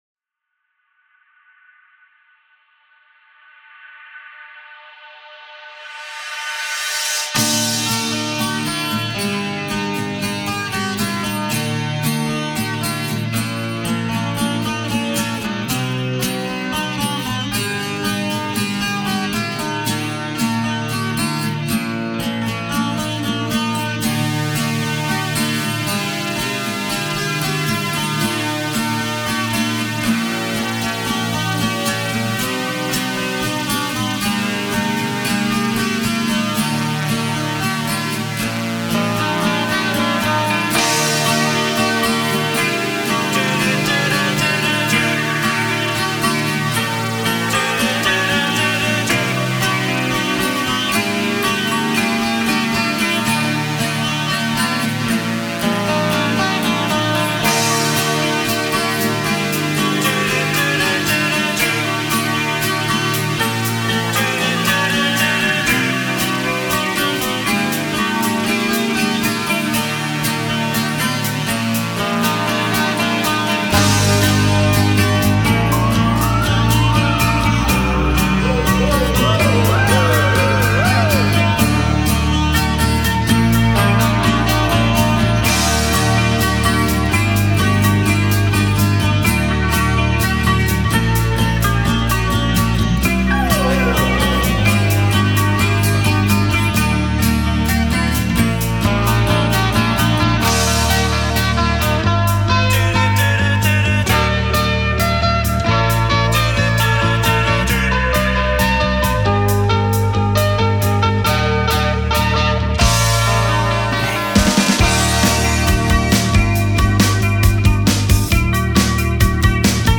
A remix I did for her, for us, for you.